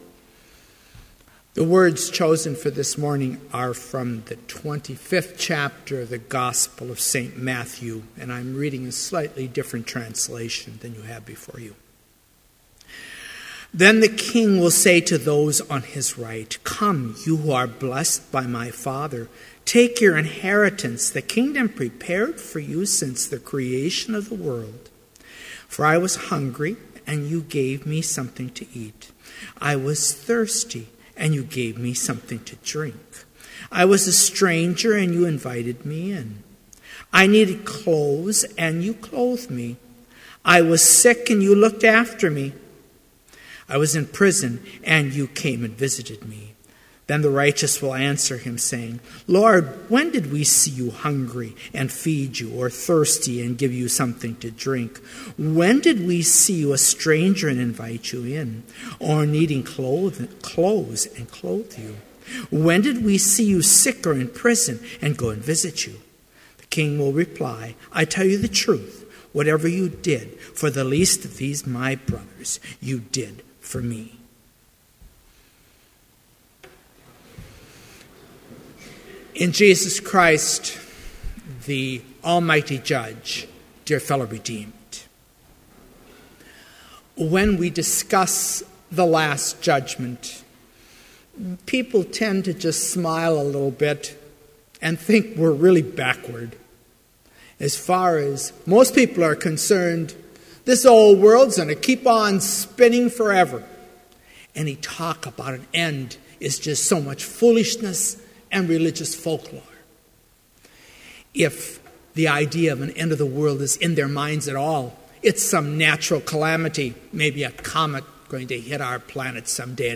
Complete service audio for Chapel - November 20, 2014